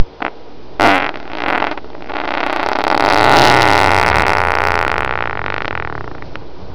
fart4.wav